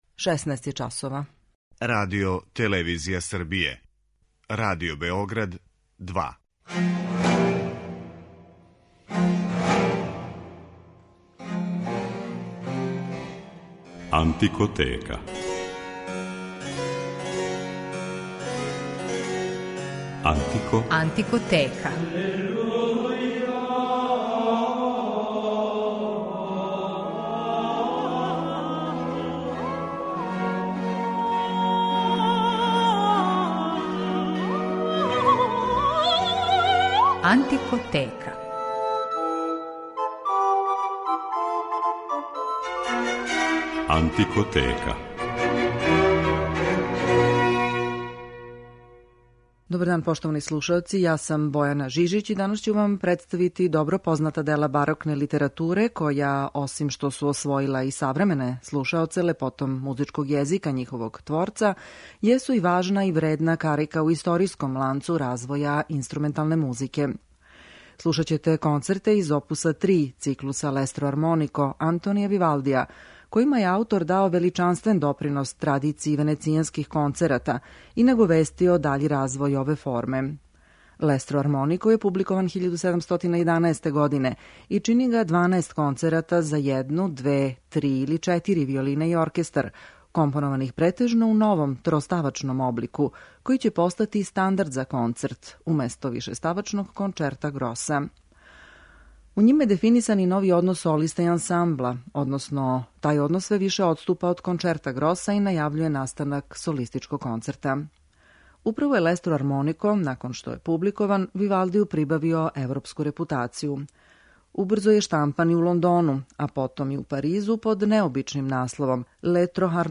У данашњој емисији представићемо једну од најубедљивијих интерпретација концерата из циклуса L'estro armonico Антонија Вивалдија којима је аутор дао величанствен допринос венецијанској традицији.
Извођења која су проистекла из ових истраживања, и која ћете данас слушати, музичари су градили на основу најстаријих познатих издања Вивалдијевог циклуса L'estro armonico, као и његових рукописа.